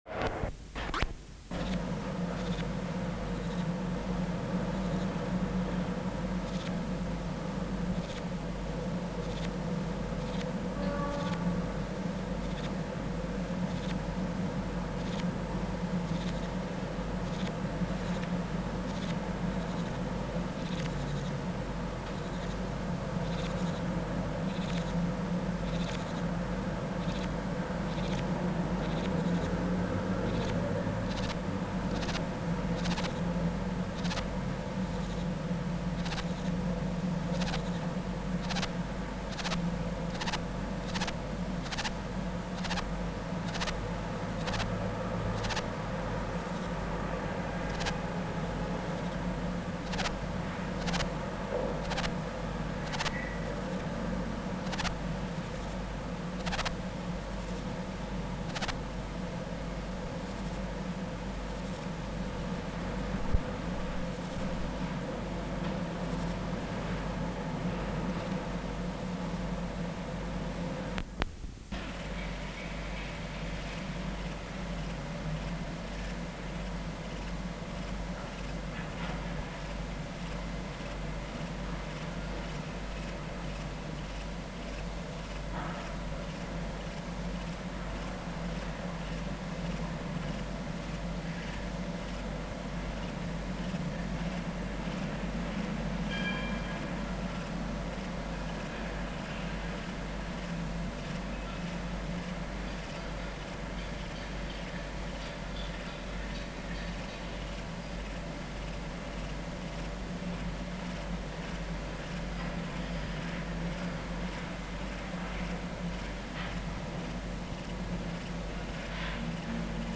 597:6 Platycleis albopunctata (003r4) | BioAcoustica
Substrate/Cage: "Aquarium cage" Biotic Factors / Experimental Conditions: 2 males stridulating
Filter: bennett high-pass filter set at 500 Hz